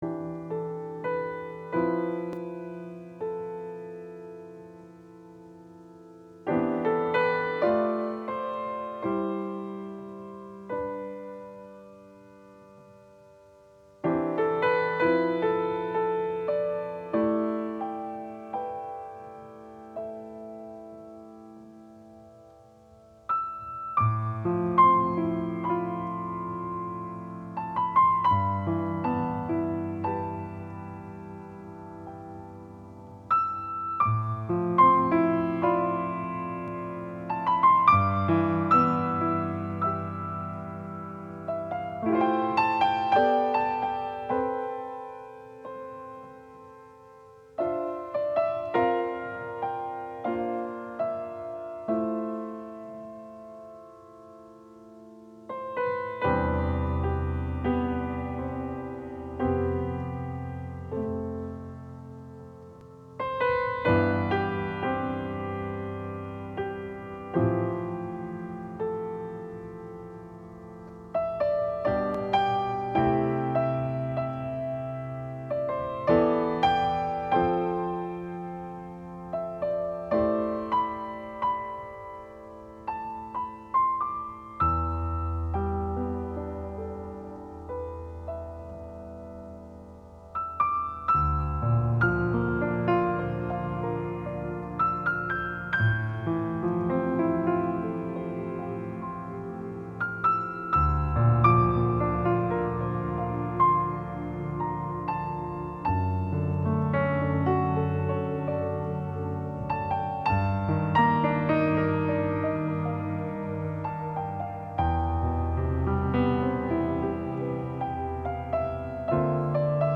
并且回到亲切的钢琴独奏从而表现出一种释然的情怀。
好聽的鋼琴曲